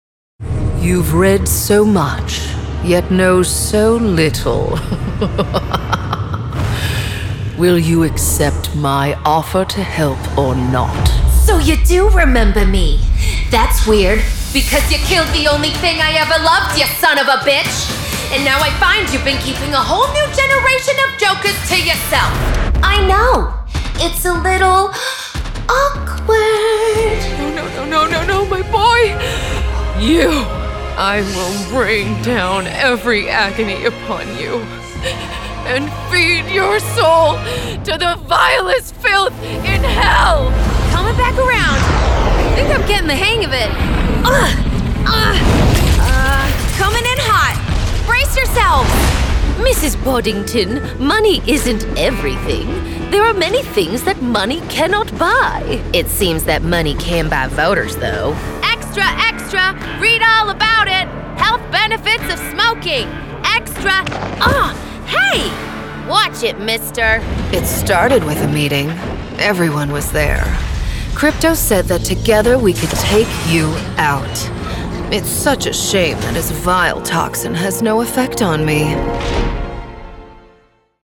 The versatile voice you can feel
Gaming Demo
trans-atlantic, New Jersey, valley-girl,